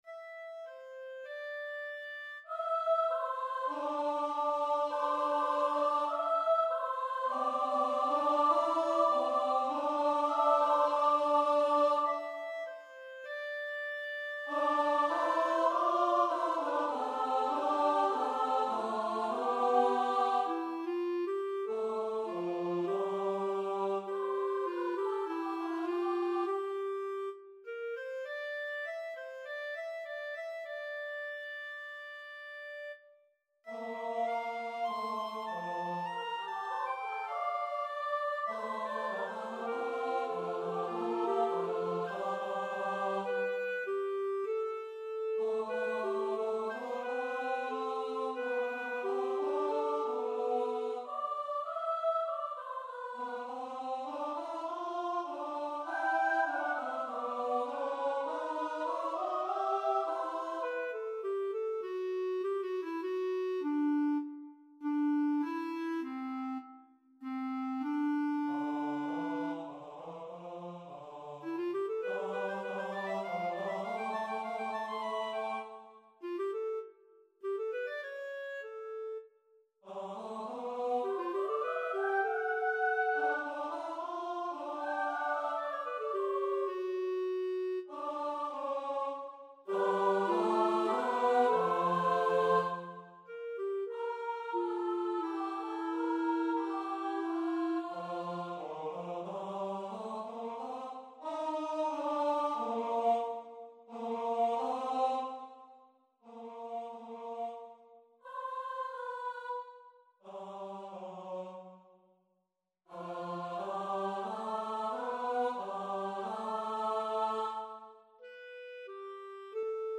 for Voices and Instruments
Soprano; Tenor; Clarinet